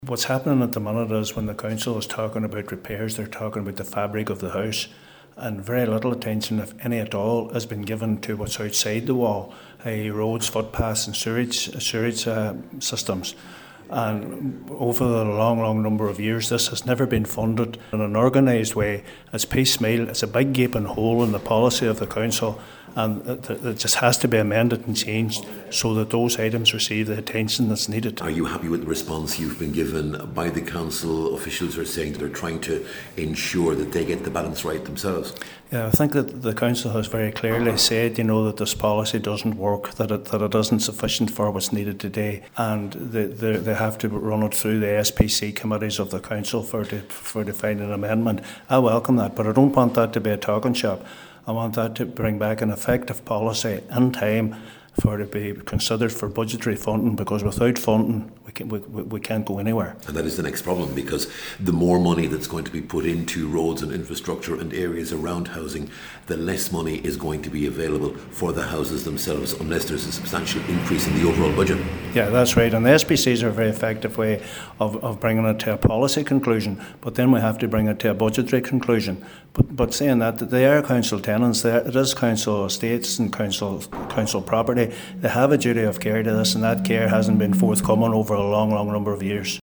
Cllr Crawford says it’s a good start…………..